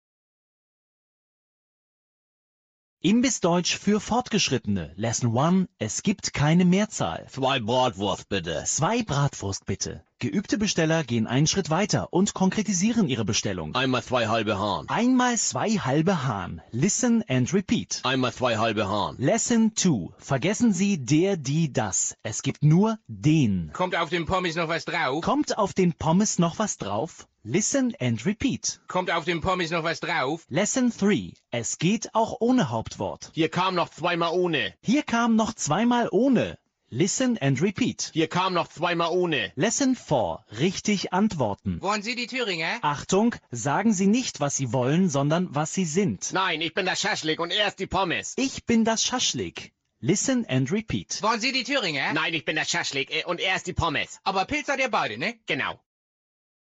» Sprachkurs Deutsch - Ruhrpott / Ruhrpott - Deutsch